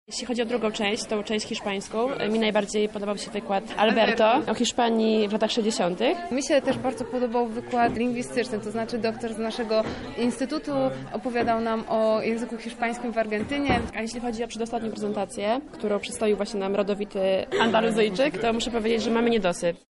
O swoich wrażeniach mówią uczestniczki konferencji.